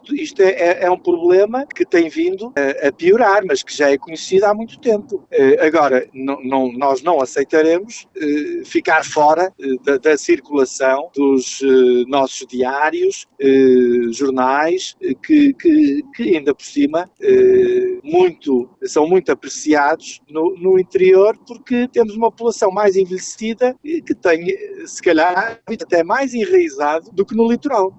O presidente da Comunidade Intermunicipal das Terras de Trás-os-Montes, Pedro Lima, rejeita esta possibilidade, lembrando que as populações do interior mantêm fortes hábitos de leitura em papel: